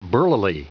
Prononciation du mot burlily en anglais (fichier audio)
Prononciation du mot : burlily